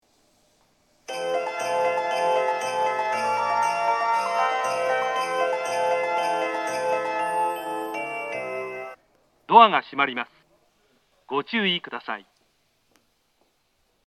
当駅の発車メロディーは音質が大変良いです。
発車メロディー
余韻切りです。